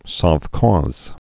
(sŏf-kôz, sôv-ôz)